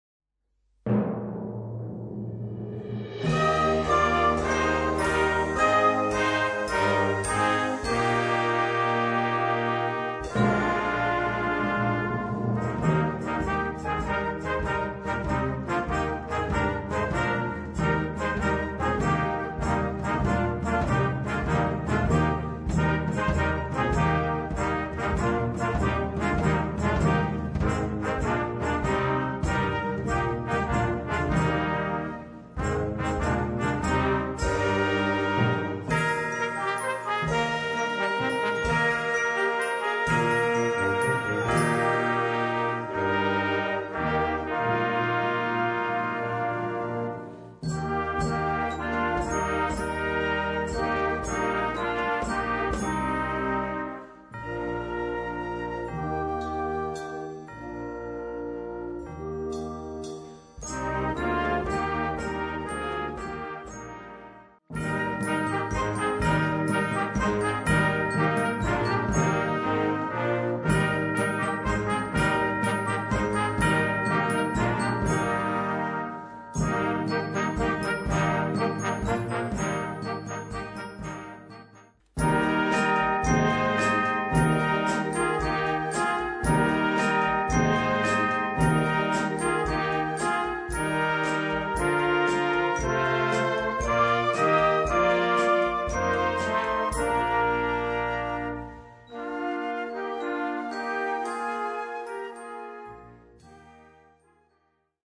Weihnachtsmusik
Noten für flexibles Ensemble, 4-stimmig + Percussion.